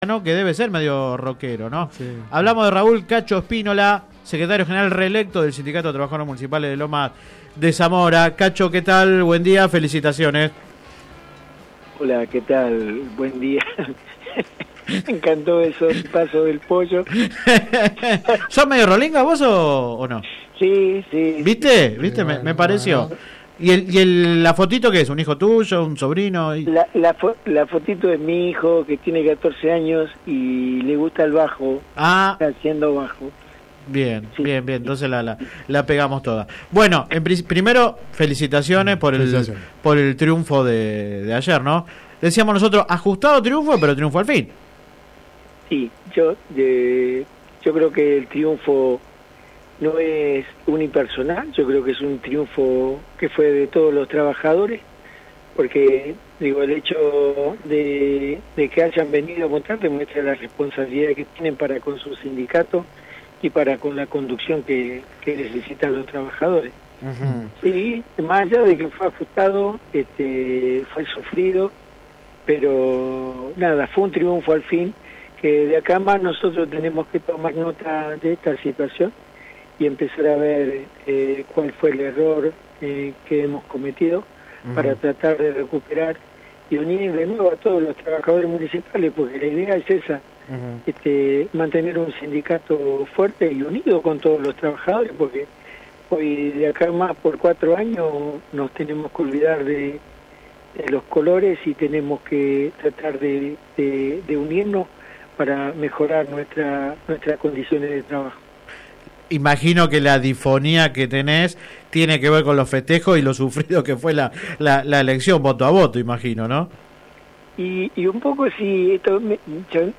Click acá entrevista radial